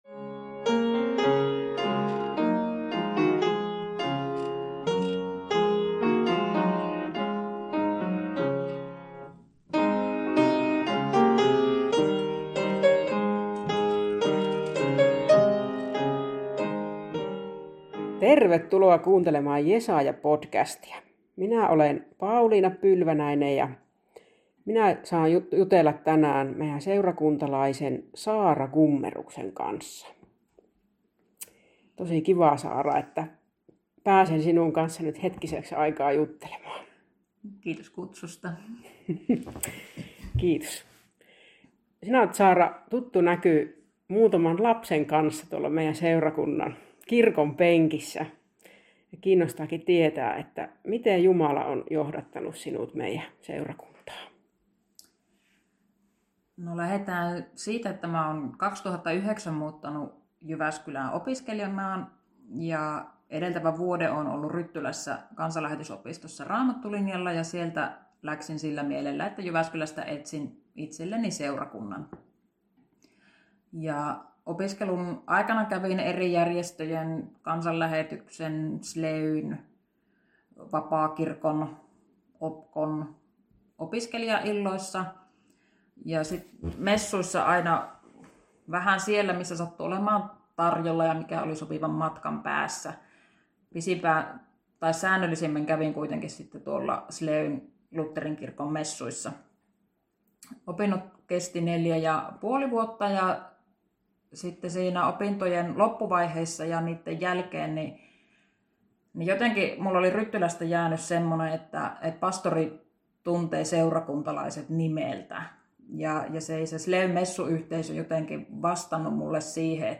Ohjelmassa haastattelemme Jesajan luterilaisen seurakunnan jäseniä siitä, miten he tulivat kutsumaan seurakuntaamme kodiksi.